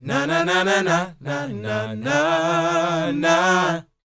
NA-NA E4B -R.wav